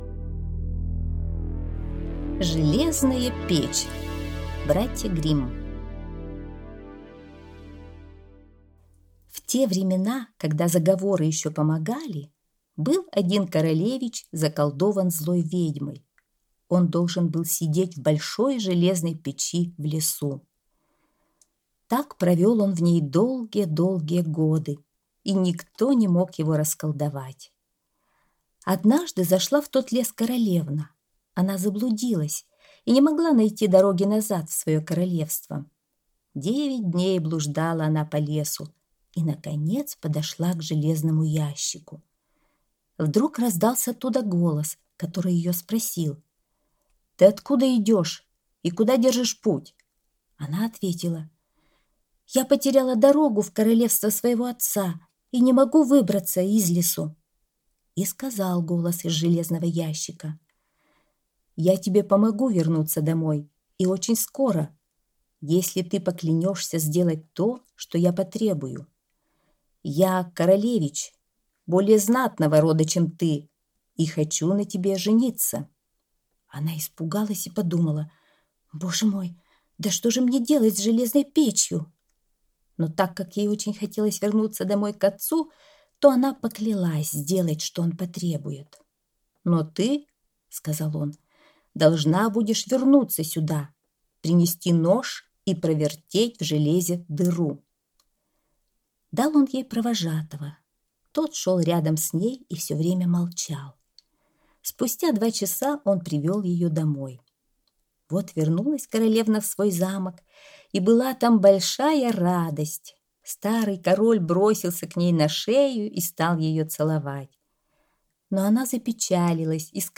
Железная печь - аудиосказка Братьев Гримм - слушать онлайн